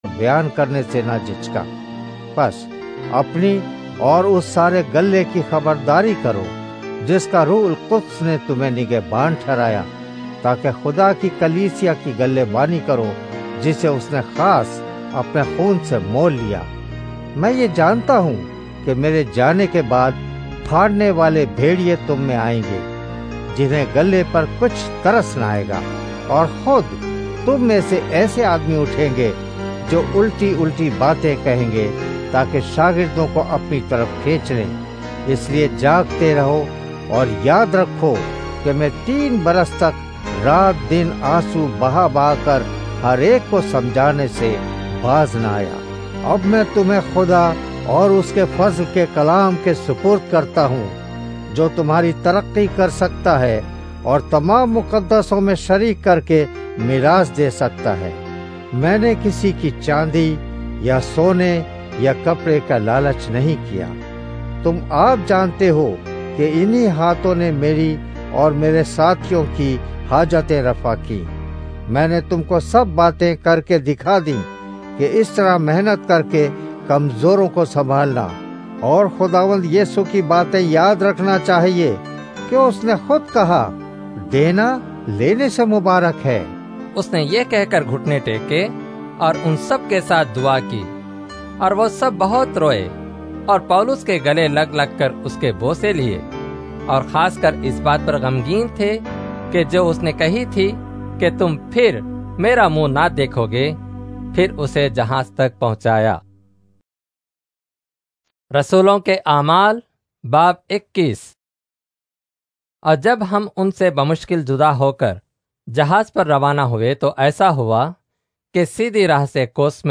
Urdu Audio Drama Bible New Testament - United Evangelical Christian Fellowship(UECF), New Jersey - Popular Christian Website Telugu Hindi Tamil Malayalam Indian Christian audio Songs and daily bible devotions